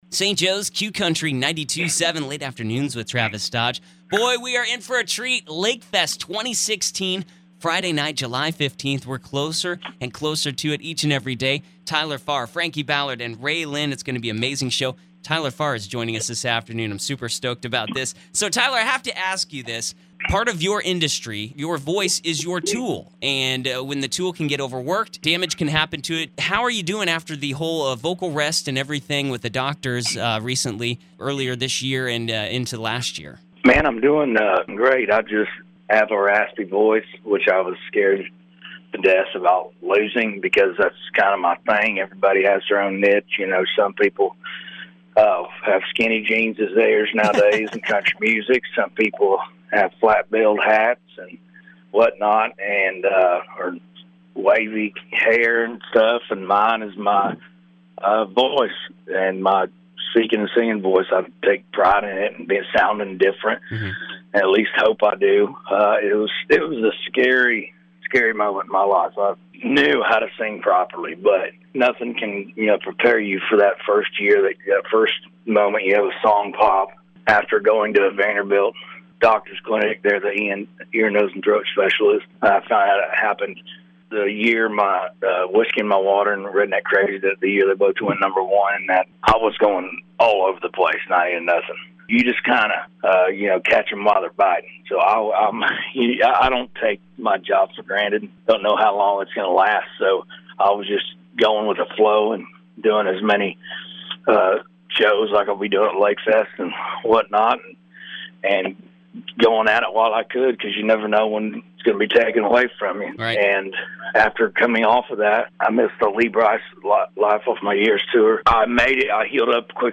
Tyler Farr Interview Leading Up To LakeFest 2016